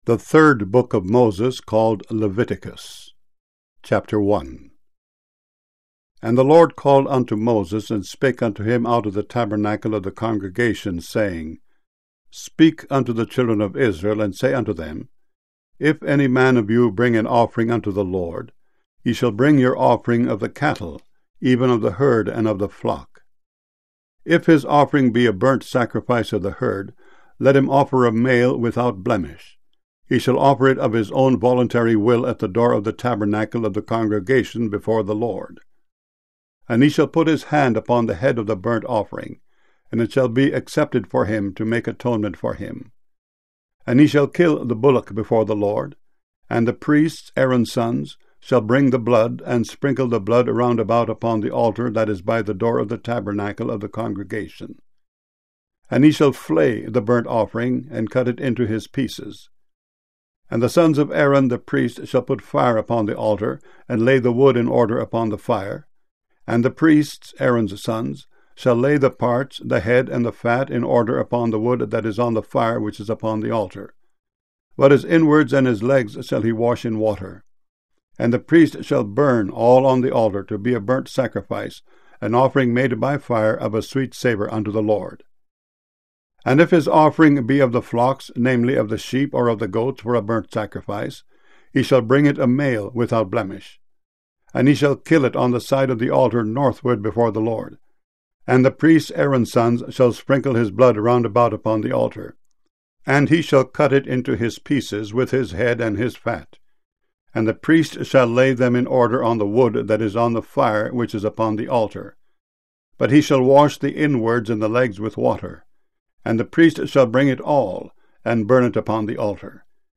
KJV Bible Chapters Mono MP3 64 KBPS